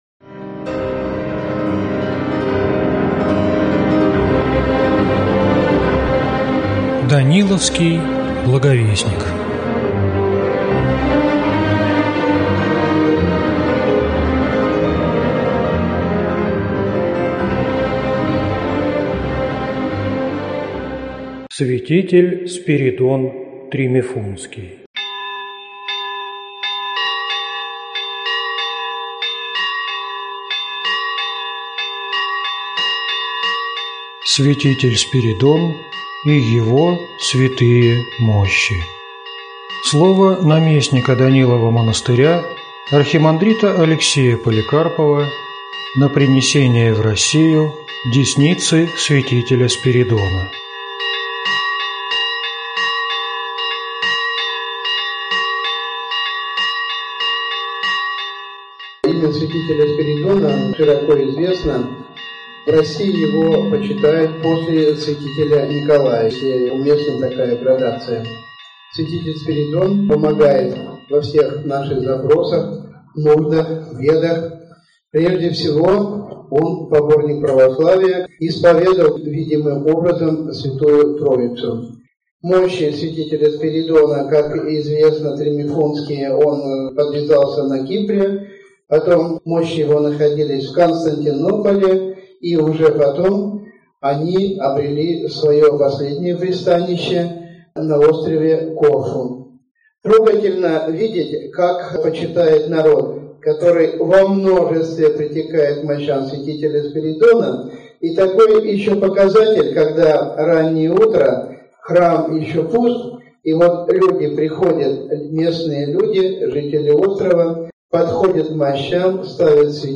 Аудиокнига Святитель Спиридон Епископ Тримифунтский | Библиотека аудиокниг